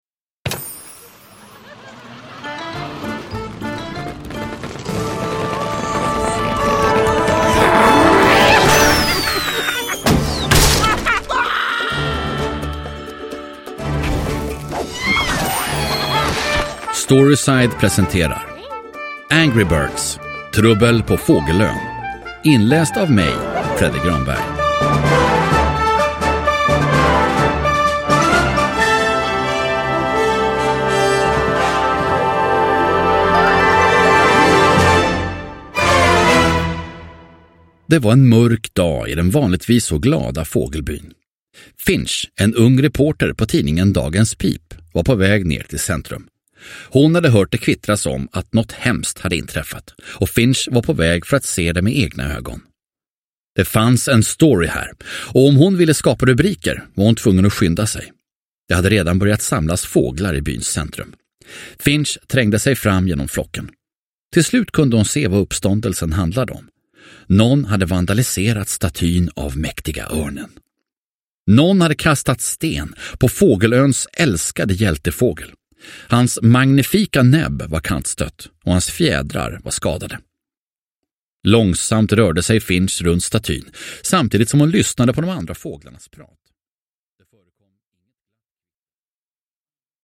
Angry Birds - Trubbel på Fågelön – Ljudbok – Laddas ner